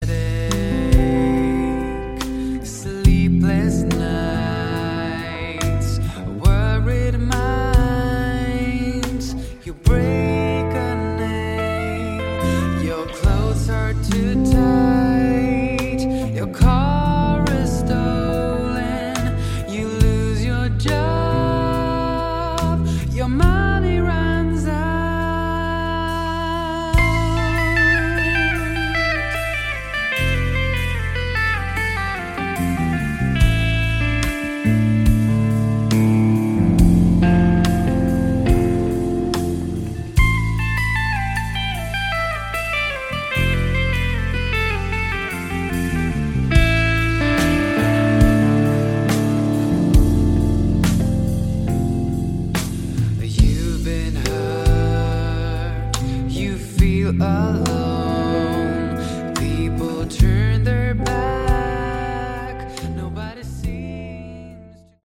Category: Prog/Hard Rock
lead vocals, bass
guitars
drums